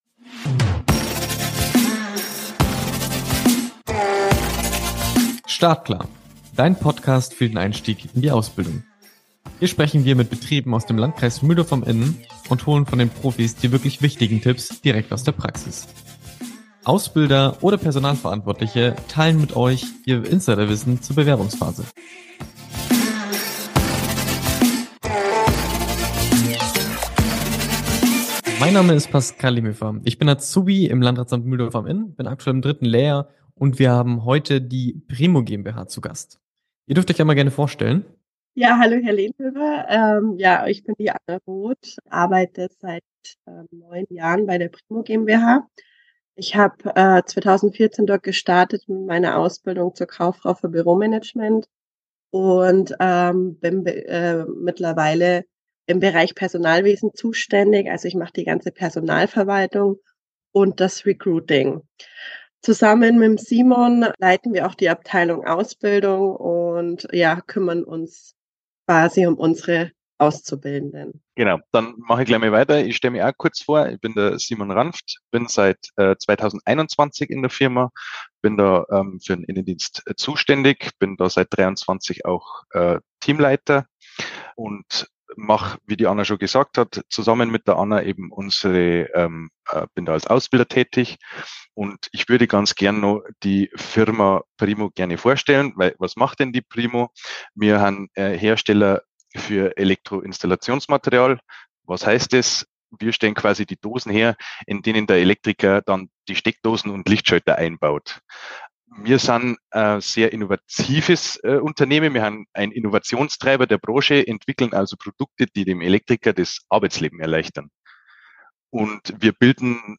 im Gespräch mit der Primo GmbH
Hier geht es zum gesamten Interview